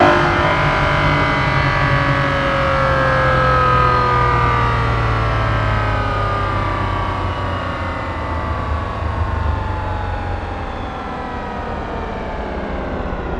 rr3-assets/files/.depot/audio/Vehicles/w12_01/w12_01_decel.wav
w12_01_decel.wav